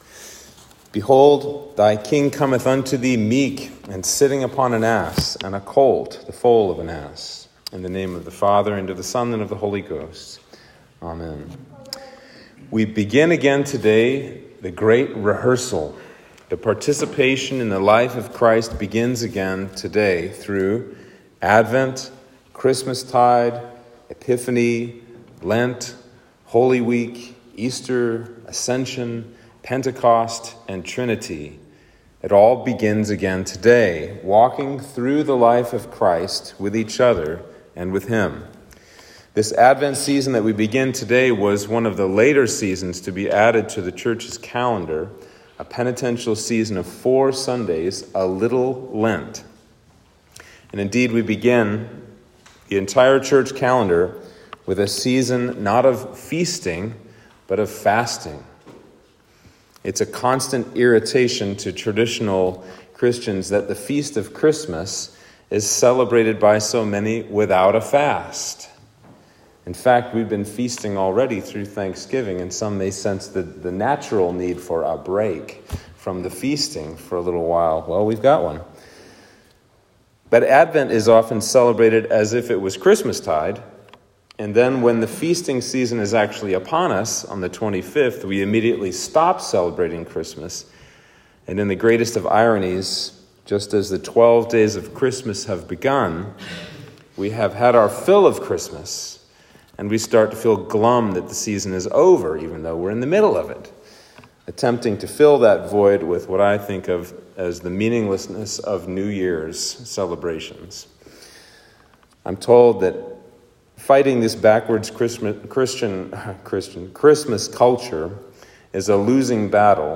Sermon for Advent 1